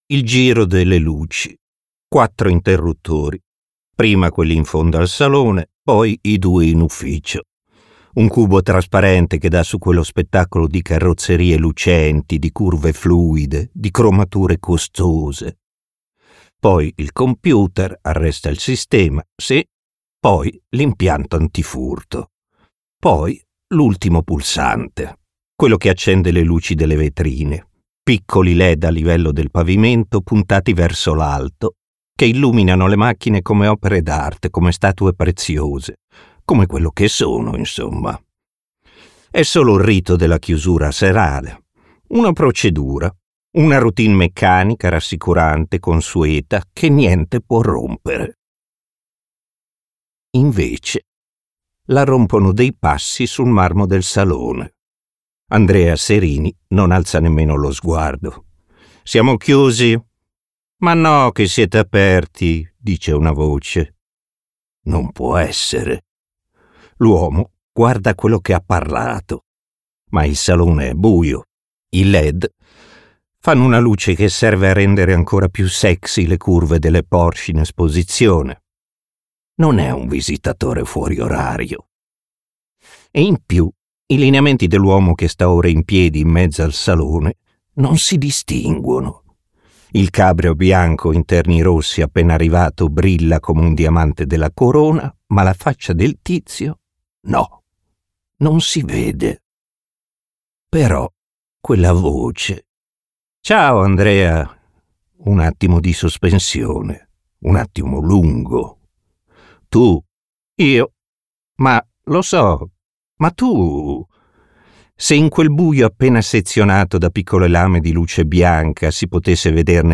letto da Gigio Alberti
Versione audiolibro integrale